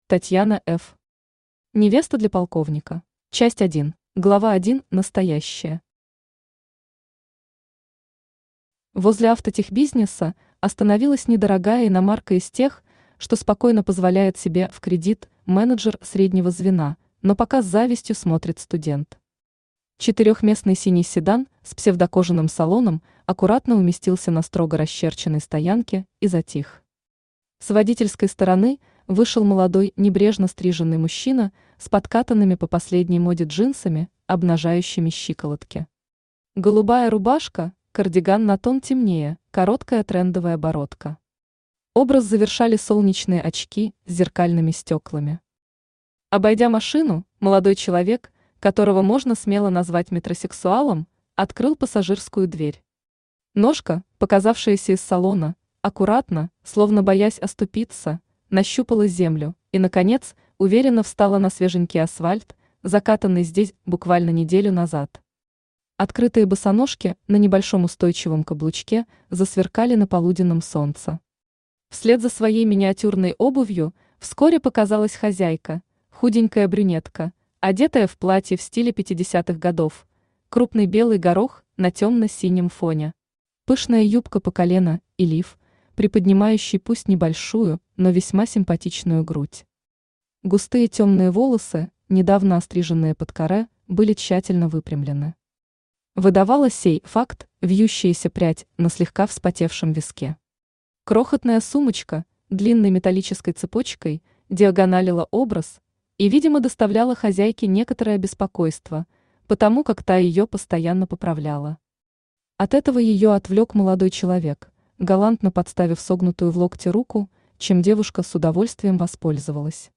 Aудиокнига Невеста для полковника Автор Татьяна Ф Читает аудиокнигу Авточтец ЛитРес.